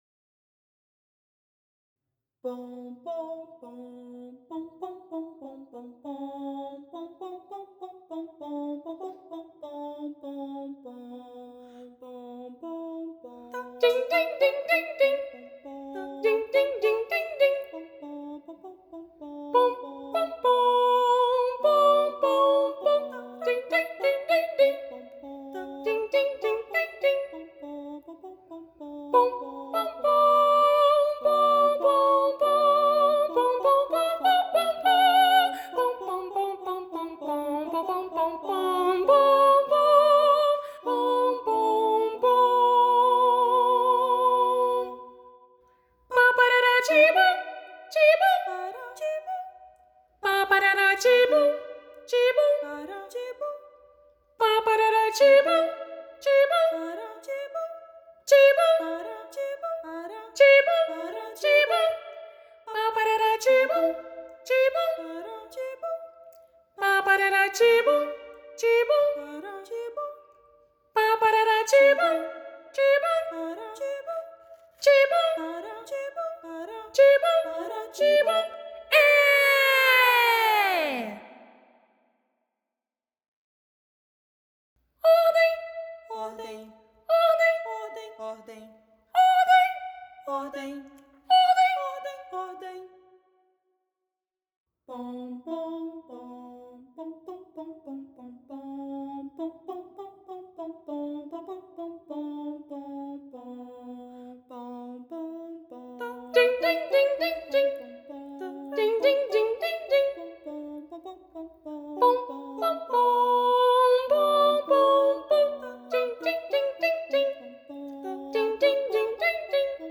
“3 Canções para os pequeninos” é um conjunto de três pequenas peças para coro infantil a três vozes.
Na segunda canção (Acalanto), Rafael Bezerra traz uma singela canção de ninar, com texto próprio, que pode ser vista como uma oração de proteção, enquanto na terceira canção (Marcha), podemos sentir o vigor das bandas militares não apenas pela rítmica utilizada como também pelo uso de onomatopeias que nos transportam para esse universo.
Voz Guia